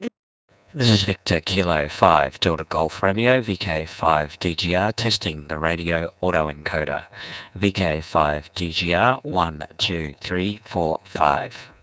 （フェージングが顕著）を経由して送信しました。
以下はデコード後の受信音声サンプルです。
デコードされたRADE V1